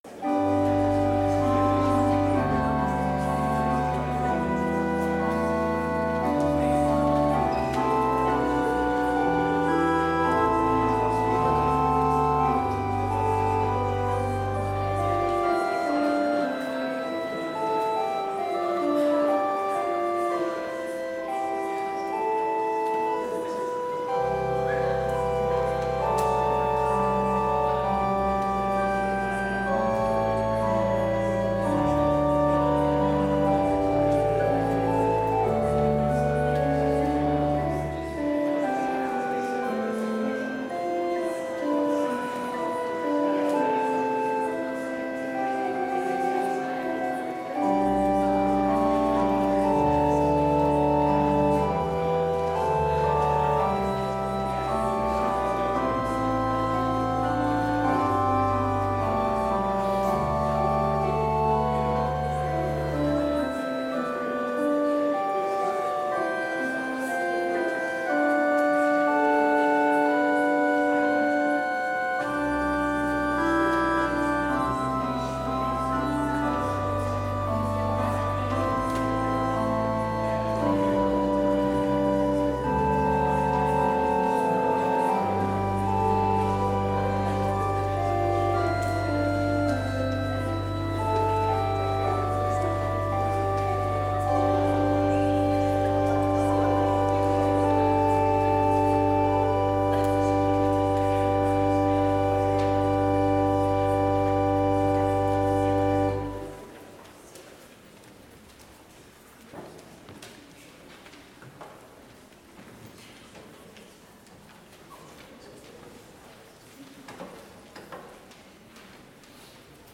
Complete service audio for Chapel - January 26, 2022